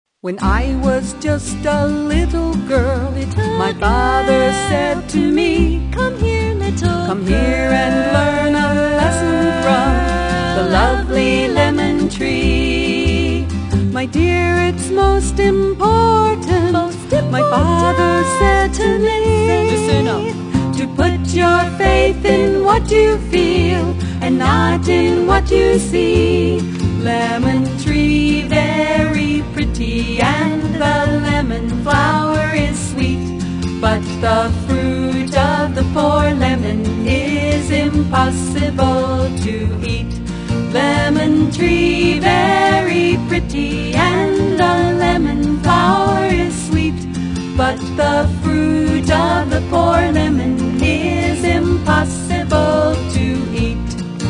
alto
guitar, mandolin
accordion, piano
upright bass
mezzo
autoharp, fiddle, soprano